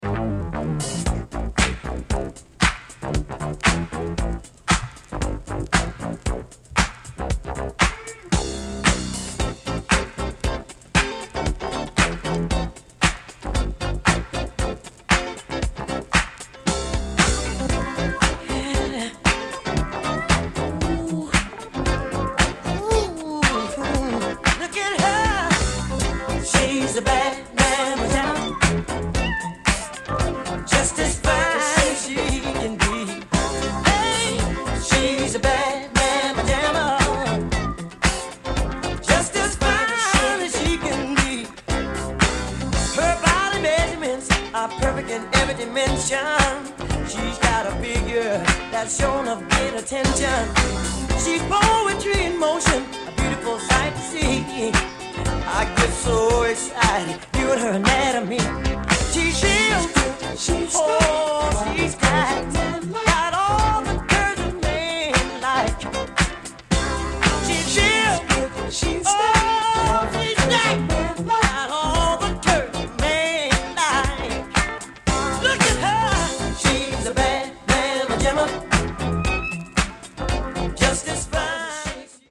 Reissue of a disco classic, originally released in 1981.